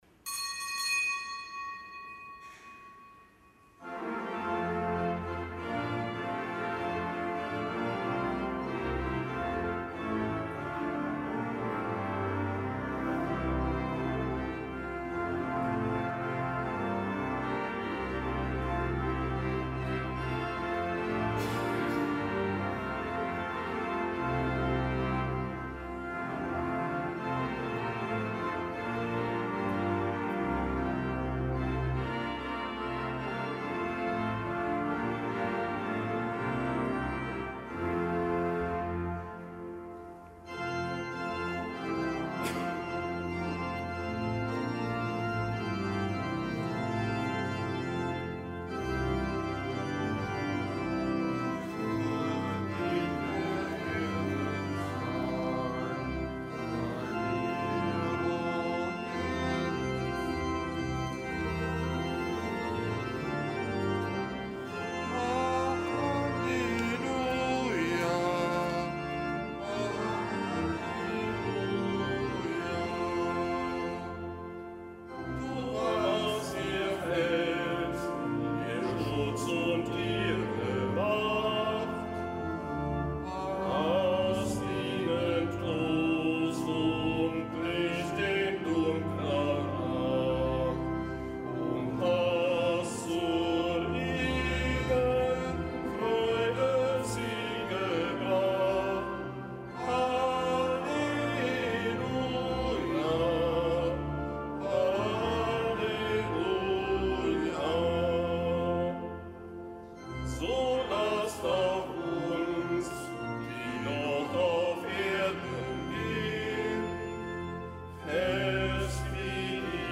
Kapitelsmesse am Fest des Heiligen Bonifatius
Kapitelsmesse aus dem Kölner Dom am Fest des Heiligen Bonifatius, Bischof, Glaubensbote in Deutschland, Märtyrer.